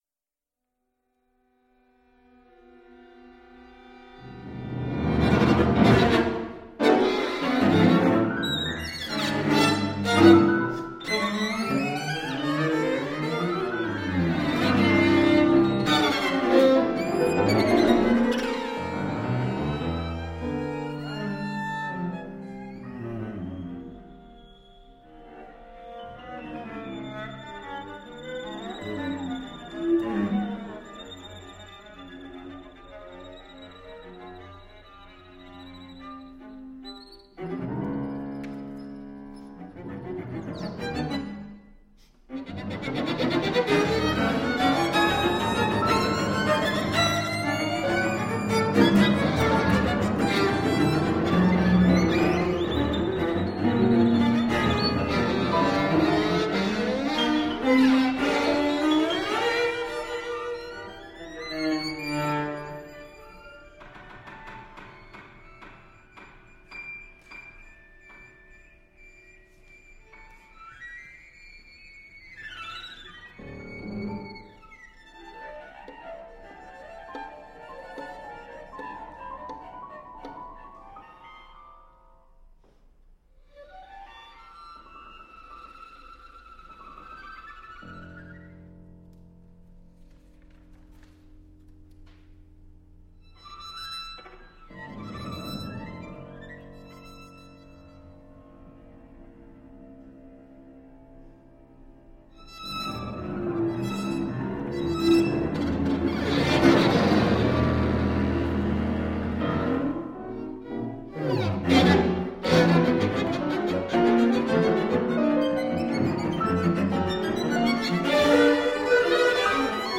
Live recording, June 13th, 2015 (Gare du Nord, Basel)
violin, viola, violoncello, piano duration: 11′